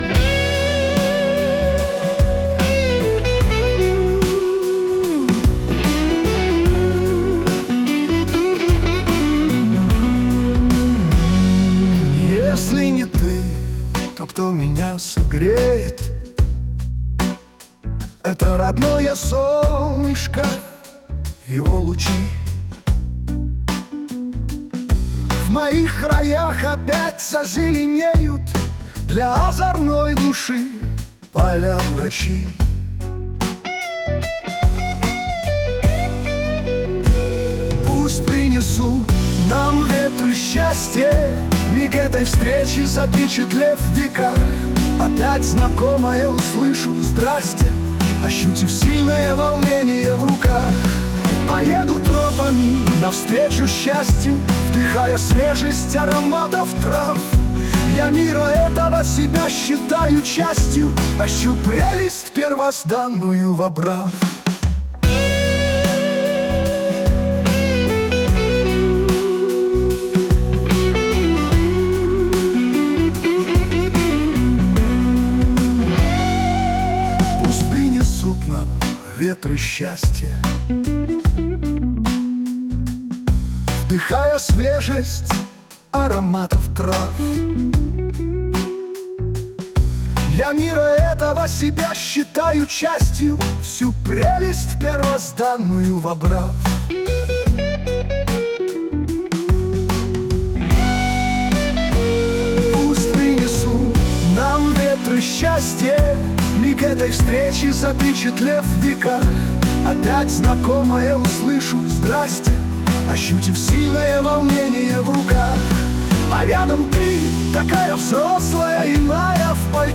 СТИЛЬОВІ ЖАНРИ: Ліричний
ВИД ТВОРУ: Пісня